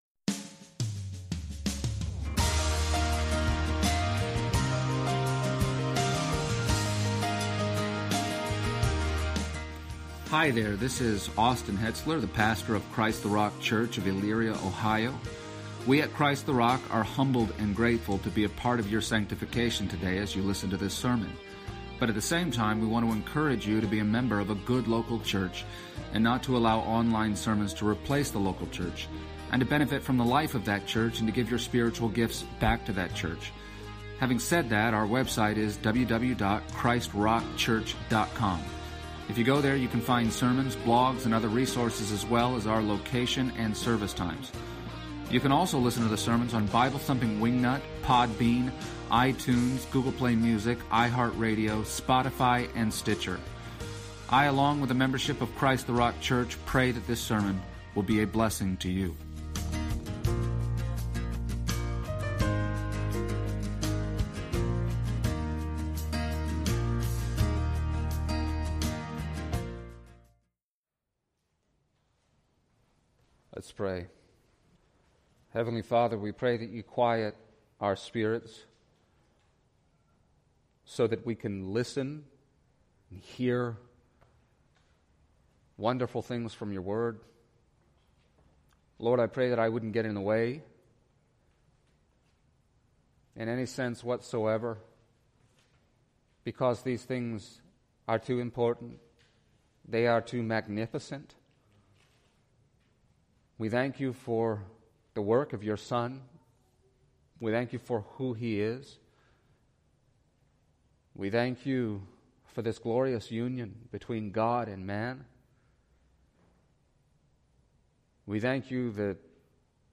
Passage: John 19:17-30 Service Type: Sunday Morning